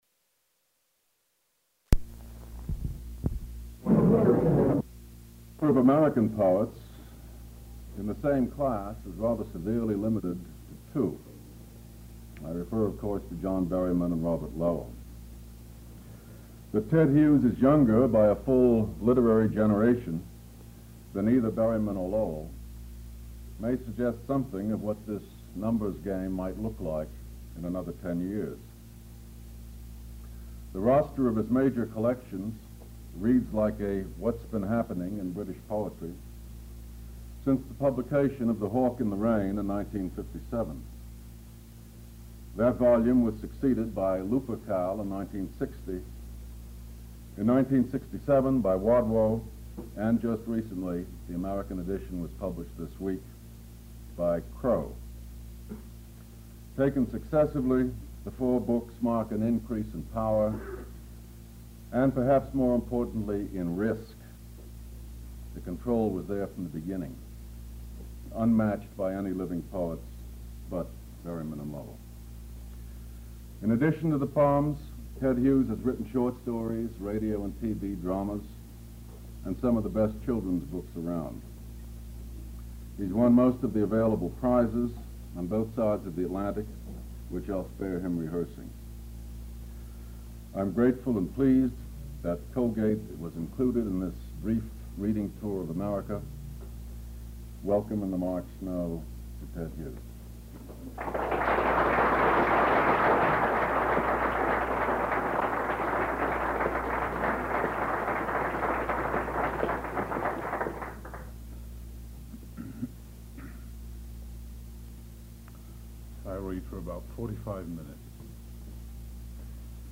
Type lectures